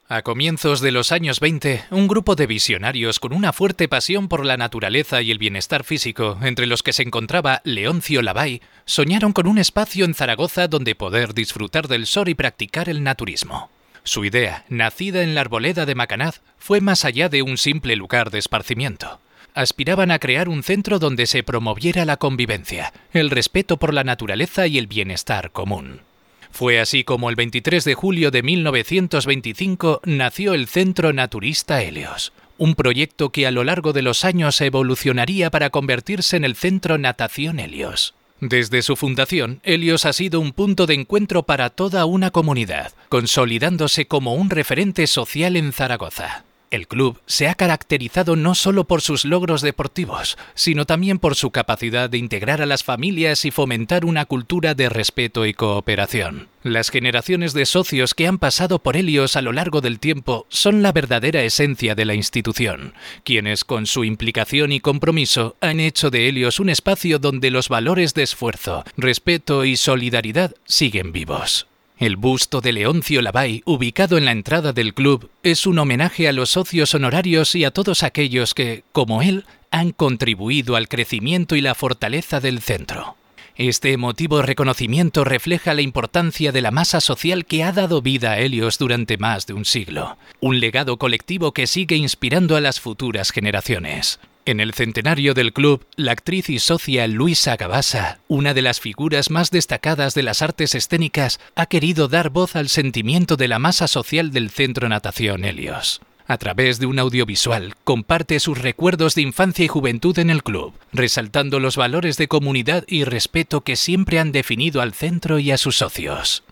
En el centenario del club, la actriz y socia Luisa Gavasa, una de las figuras más destacadas de las artes escénicas, ha querido dar voz al sentimiento de la masa social del Centro Natación Helios. A través de un audiovisual, comparte sus recuerdos de infancia y juventud en el club, resaltando los valores de comunidad y respeto que siempre han definido al centro y a sus socios.